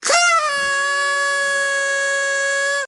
Noisemaker
yt_eKnsqClIT4o_noisemaker.mp3